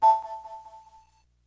menu-open.mp3